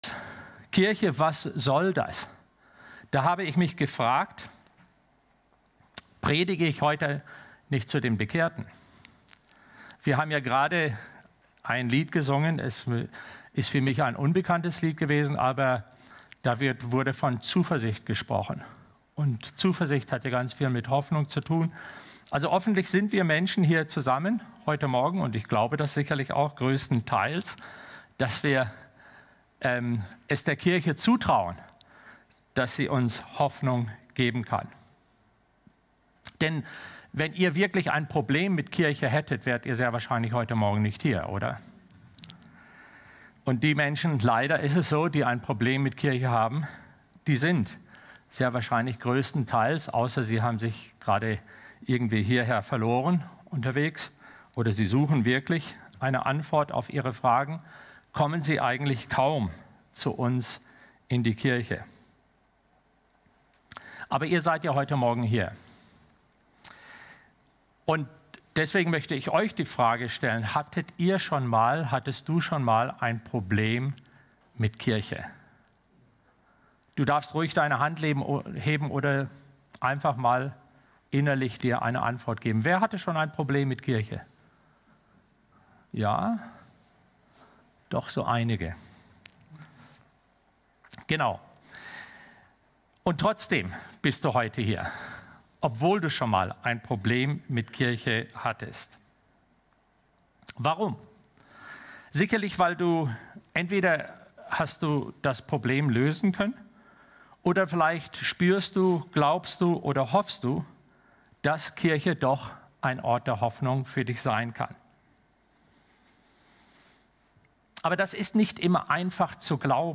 Gottesdienst-vom-9.-November-2025.mp3